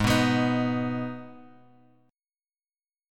G#m chord {4 2 x 4 4 x} chord
Gsharp-Minor-Gsharp-4,2,x,4,4,x.m4a